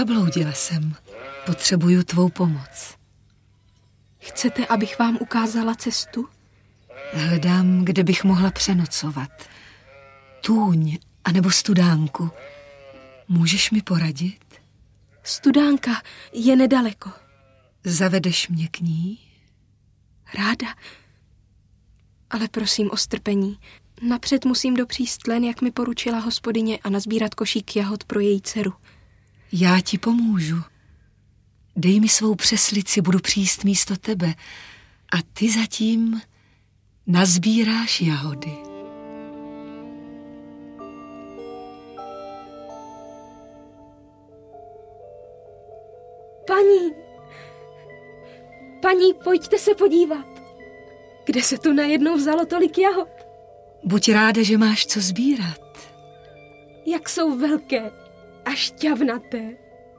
Audiobook
Read: Eliška Balzerová